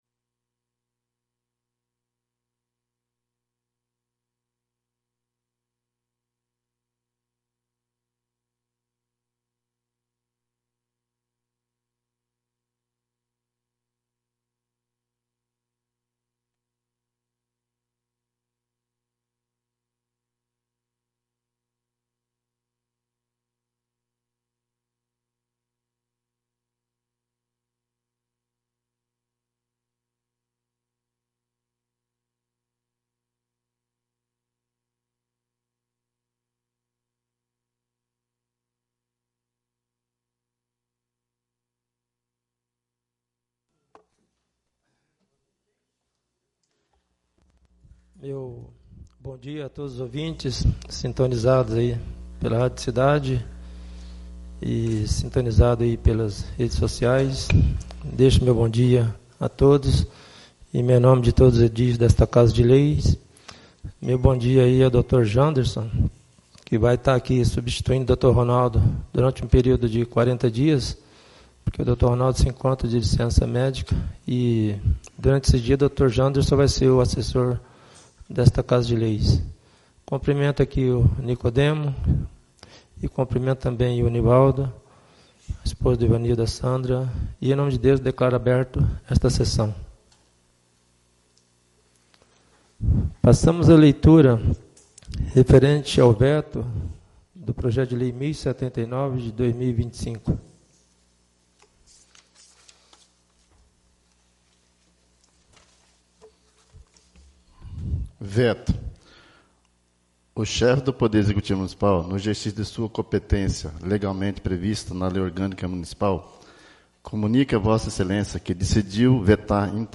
10° SESSÃO ORDINÁRIA DO DIA 04 DE AGOSTO DE 2025